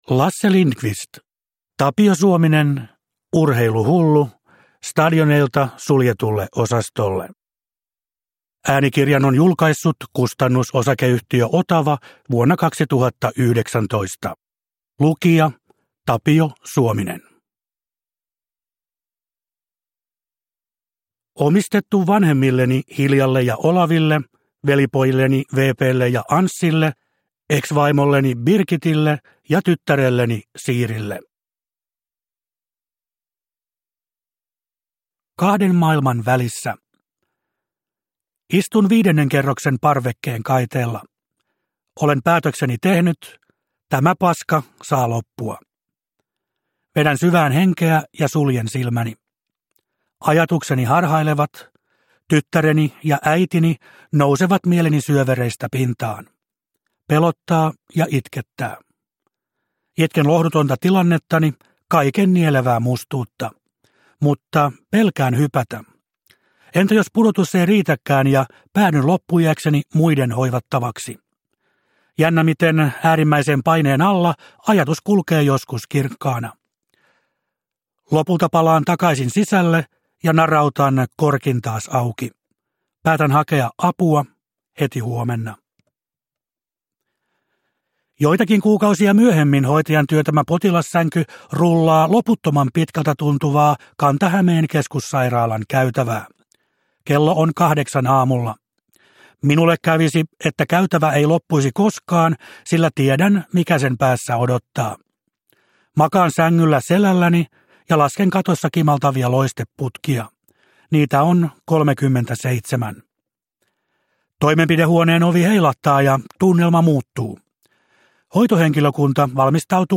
Urheiluhullu – Ljudbok – Laddas ner